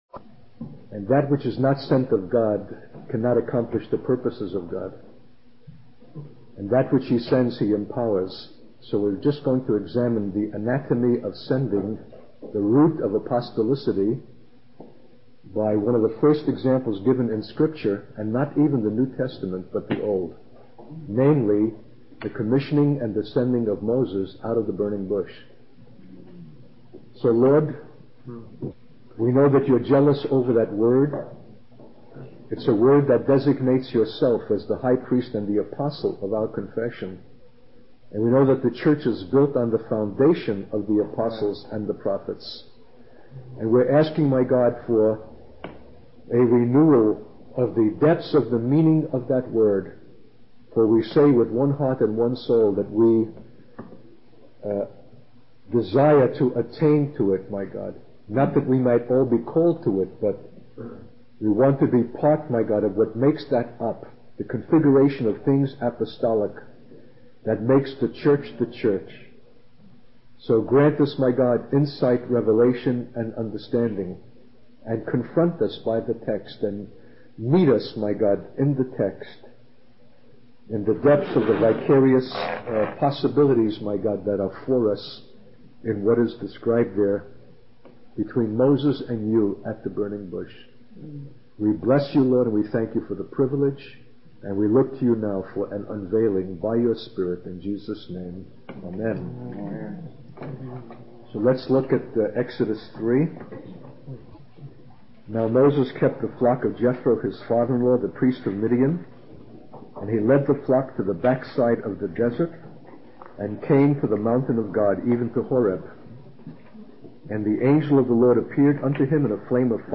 A classic message for those contemplating ministry. A 1993 message.